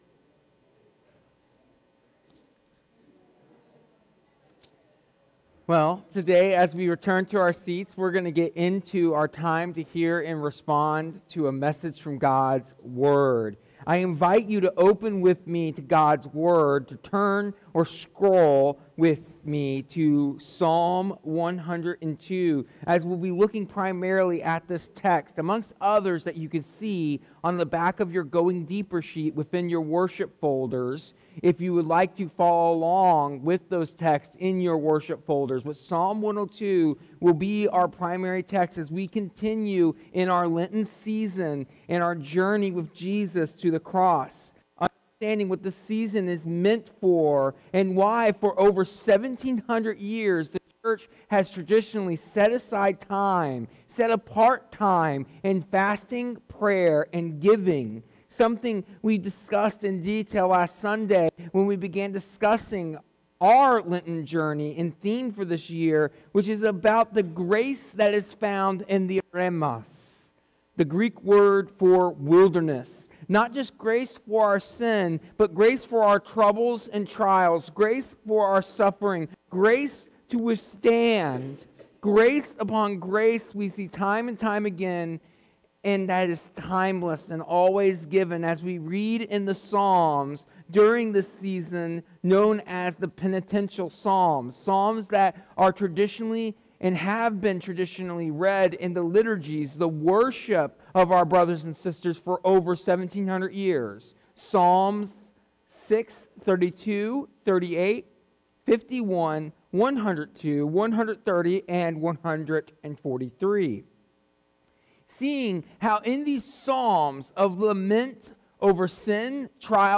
Using the image of threshing wheat, the sermon explains how God uses hardship to strip away sin and self-reliance, forming Christ in us.